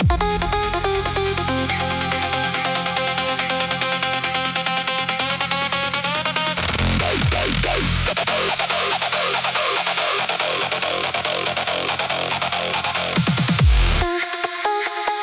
Cyberpunk EDM